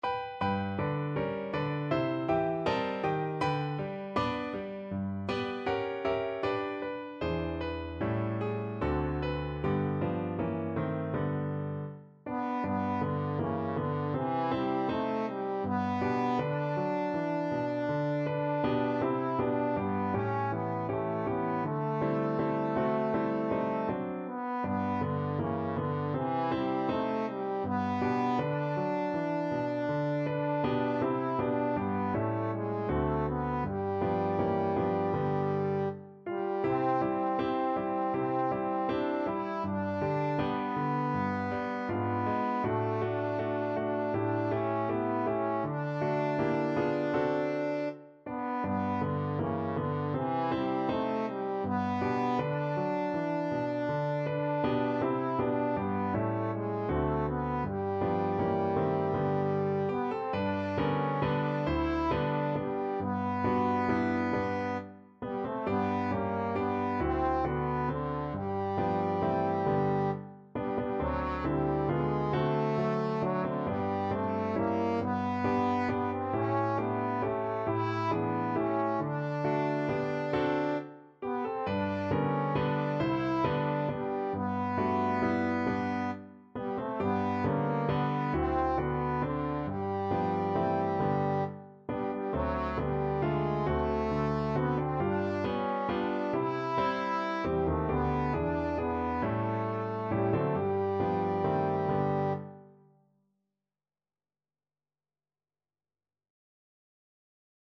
Trombone
G major (Sounding Pitch) (View more G major Music for Trombone )
4/4 (View more 4/4 Music)
Andante
Pop (View more Pop Trombone Music)